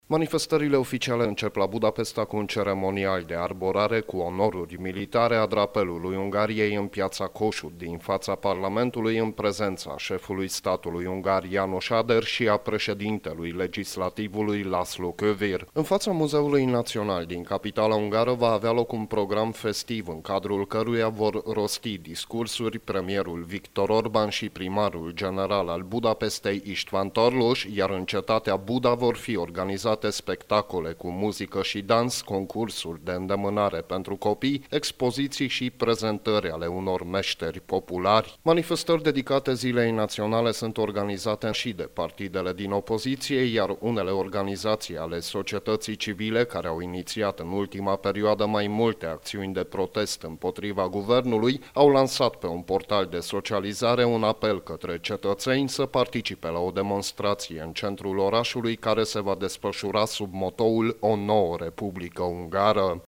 Puterea şi opoziţia organizează manifestări separate, după cum transmite corespondentul nostru